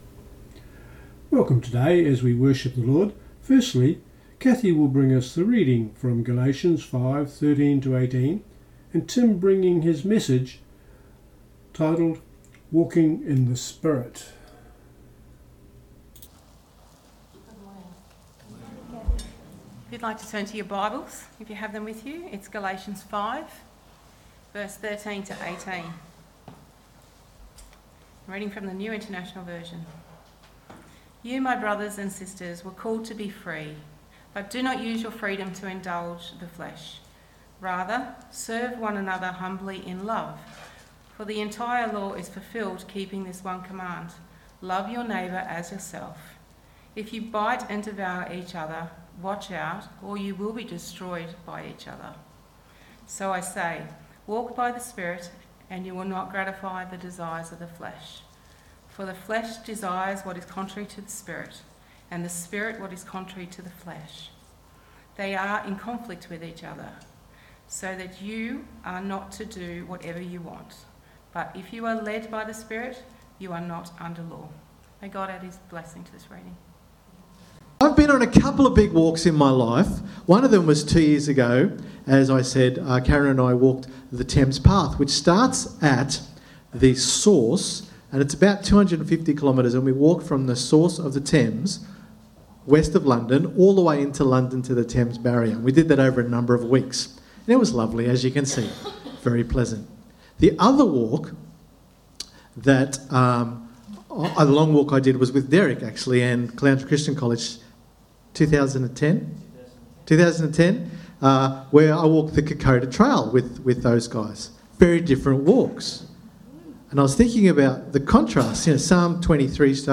The reading is from Galatians 5: 13-18